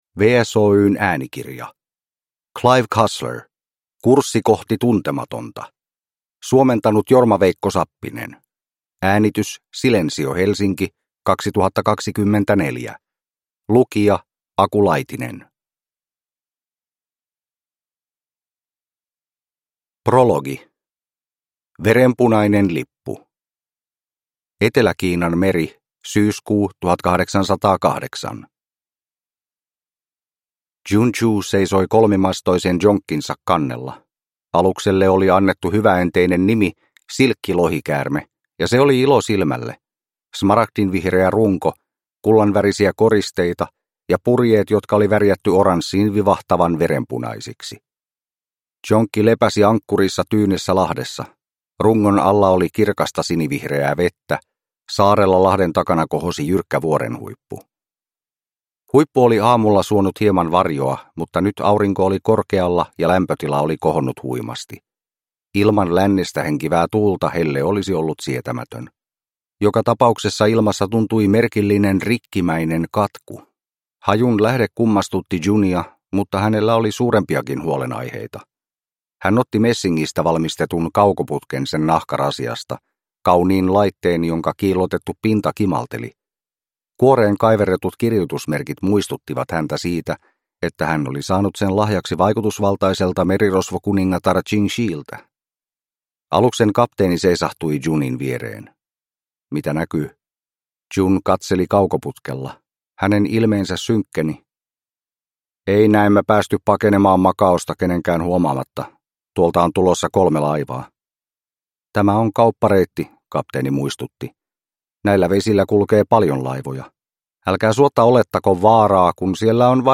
Kurssi kohti tuntematonta – Ljudbok